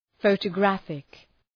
Προφορά
{,fəʋtə’græfık}